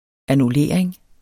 Udtale [ anuˈleˀɐ̯eŋ ]